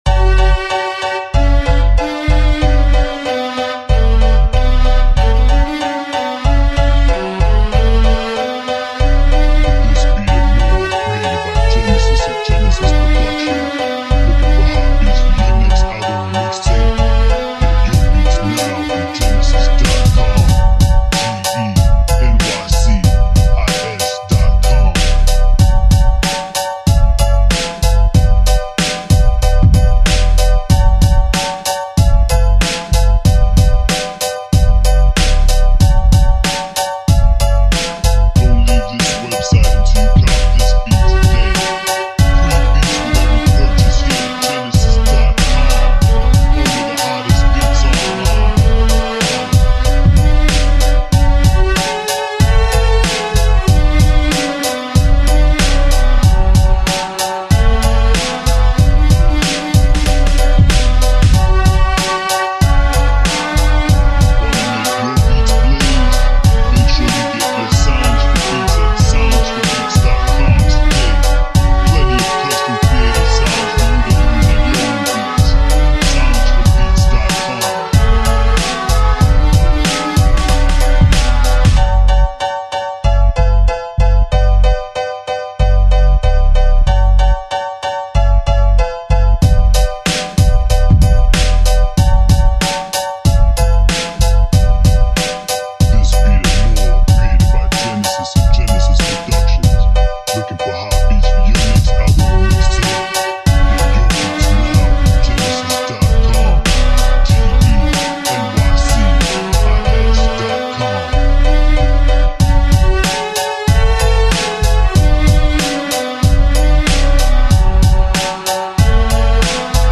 Boom Bap Old School Hip Hop Beat